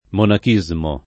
monachismo [ monak &@ mo ] → monachesimo